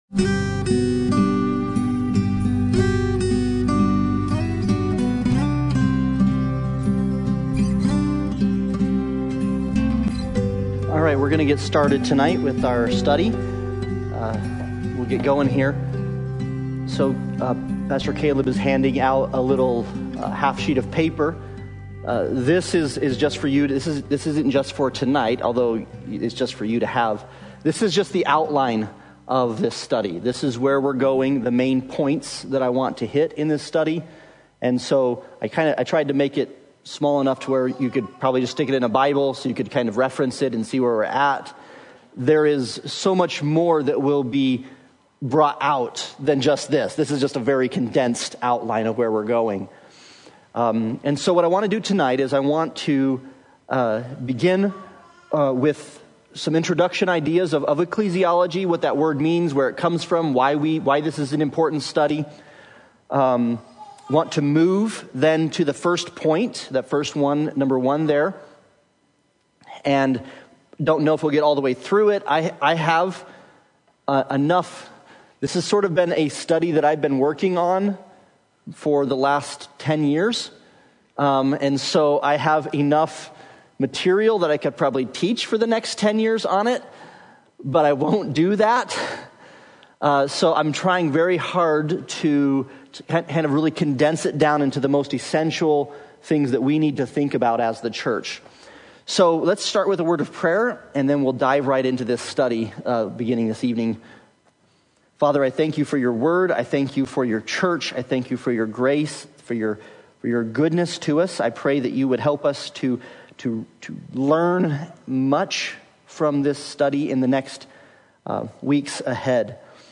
Sunday Bible Study